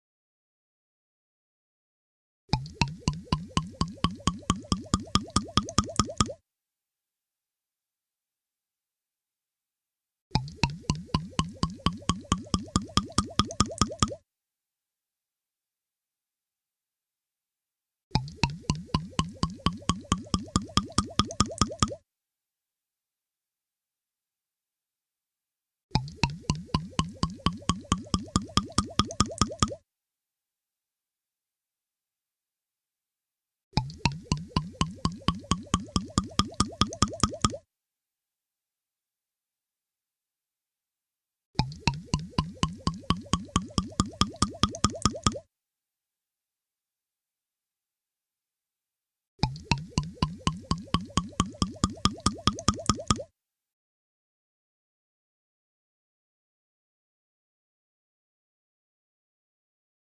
دانلود آهنگ آب خوردن در کارتن ها از افکت صوتی انسان و موجودات زنده
دانلود صدای آب خوردن در کارتن ها از ساعد نیوز با لینک مستقیم و کیفیت بالا
جلوه های صوتی